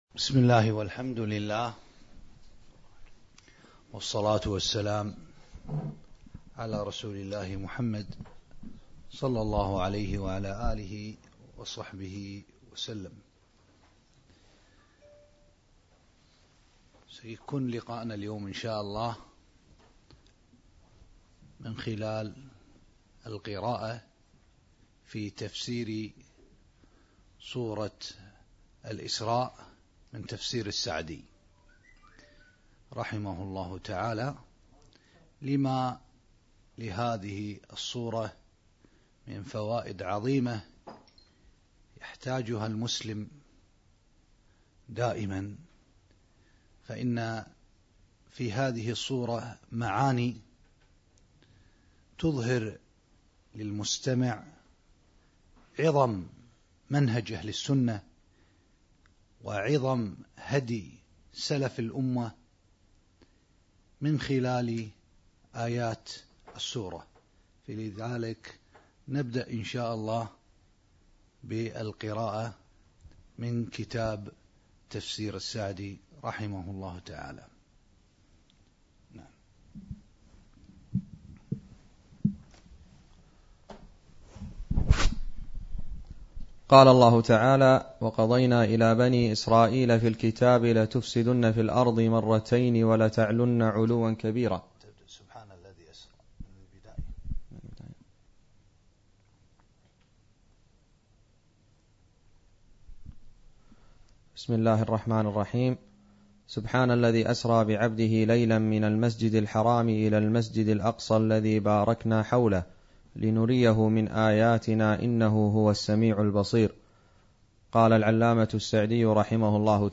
القسم: الدروس المنقولة عبر إذاعة النهج الواضح